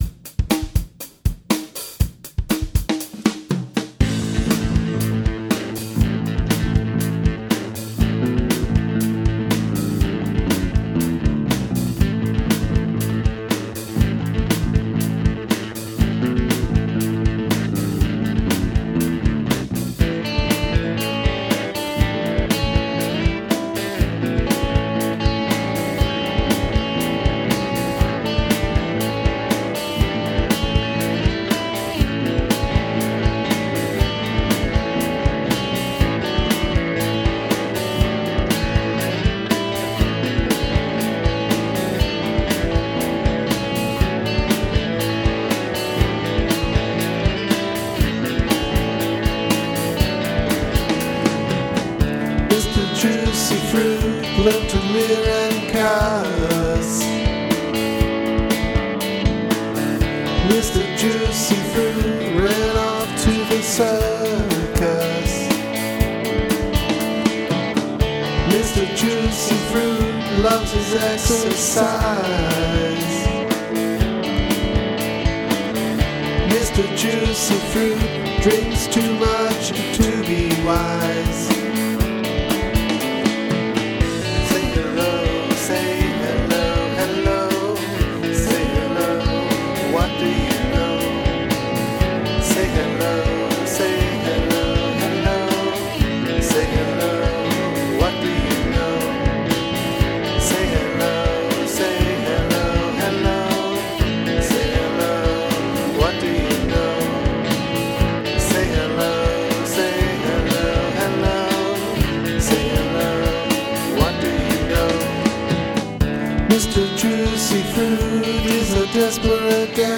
On those demos, all the instruments are live except the drums which are built-in loops.